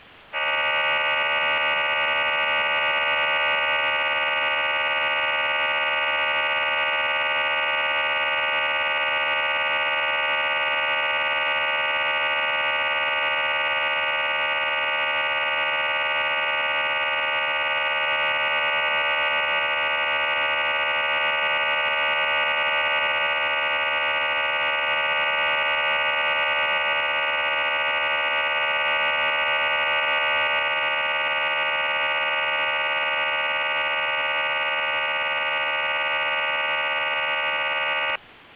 Начало » Записи » Радиоcигналы классифицированные
Принято 2015-05-17 в 11:24 на 17297,5 kHz